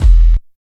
22 NOISE KIK.wav